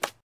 throwing_arrow_hit2.ogg